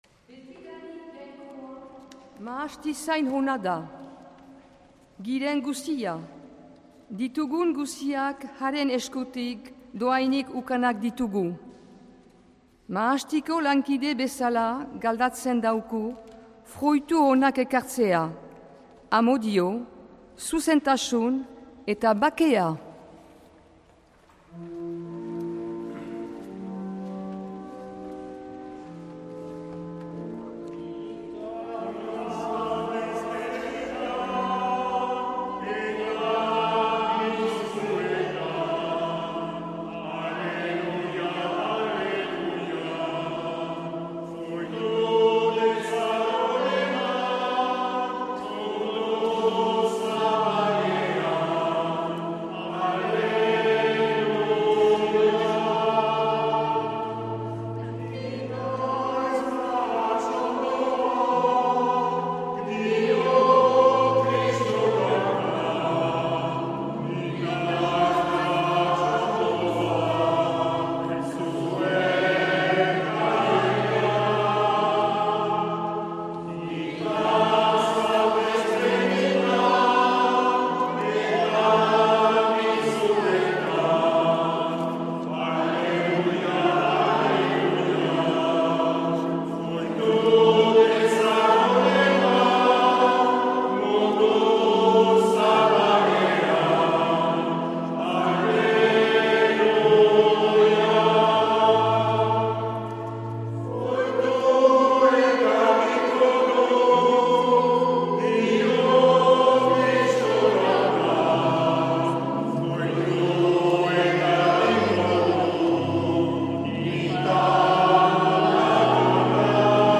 2023-10-08 Urteko 27. Igandea A - Uztaritze